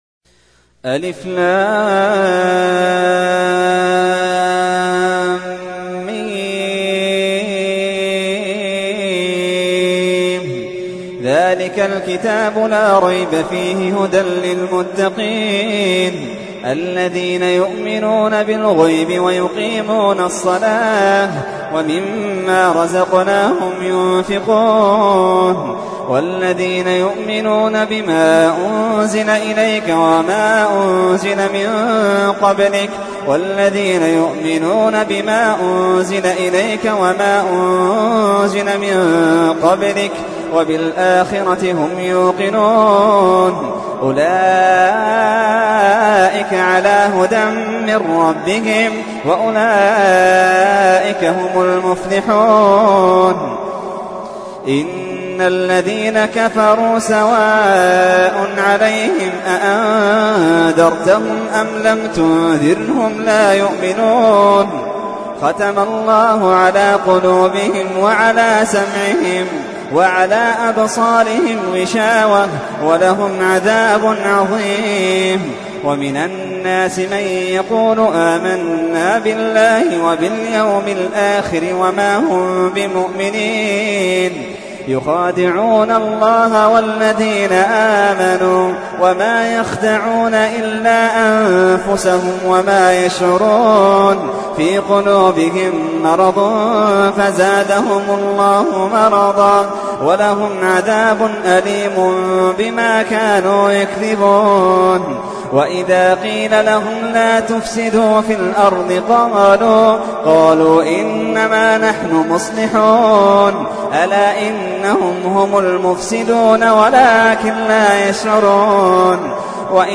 تحميل : 2. سورة البقرة / القارئ محمد اللحيدان / القرآن الكريم / موقع يا حسين